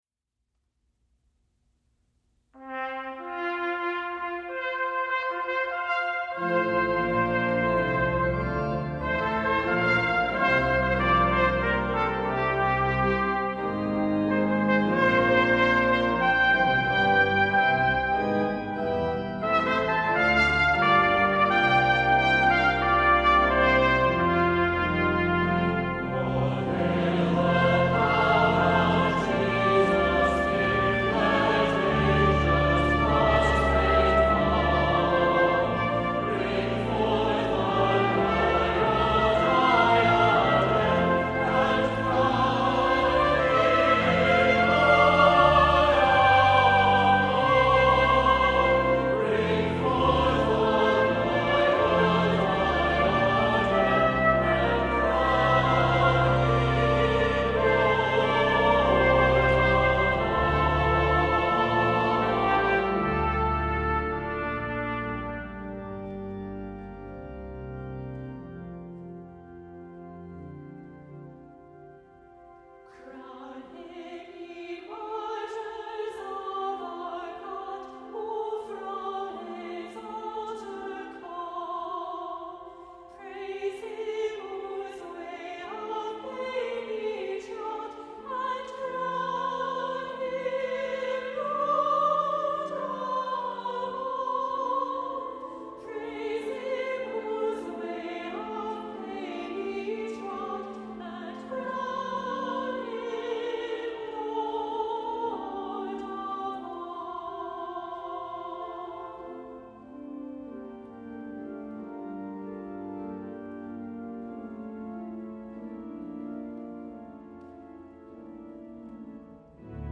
• Music Type: Choral
• Voicing: Congregation, SATB
• Accompaniment: Organ, Trumpet